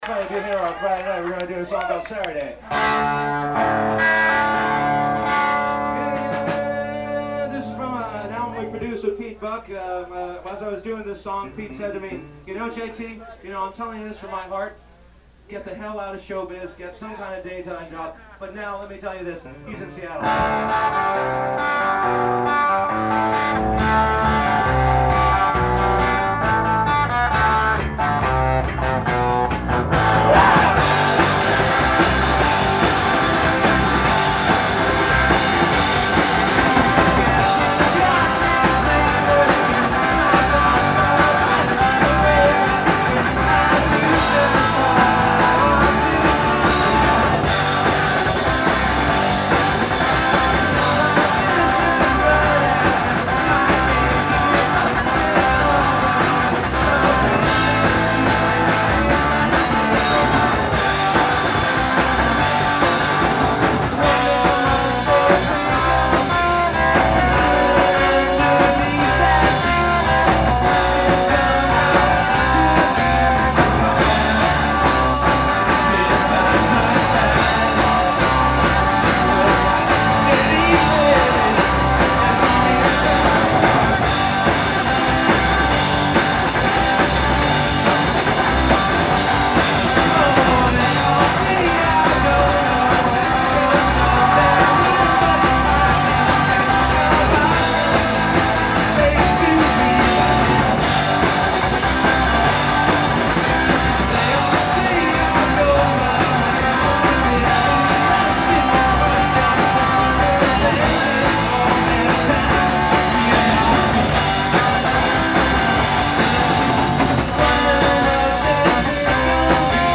Khyber Pass, Philadelphia 11-25-94